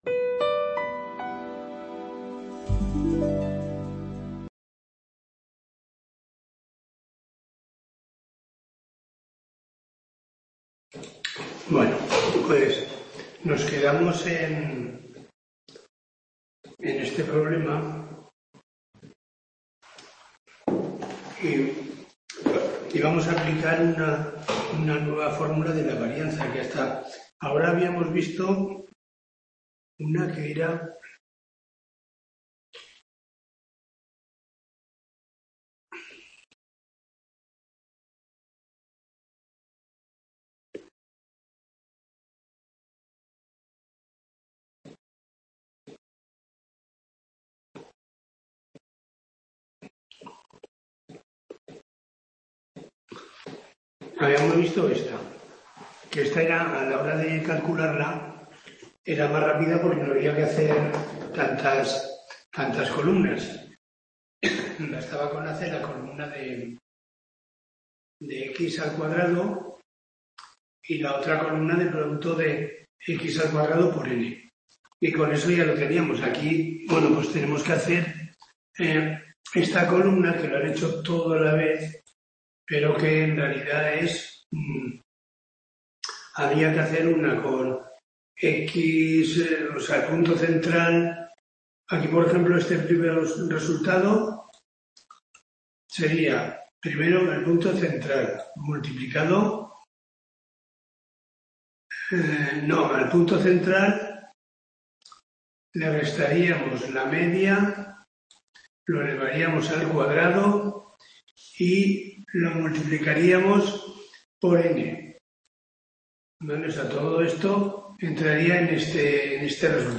Tutoría de 05/03/2025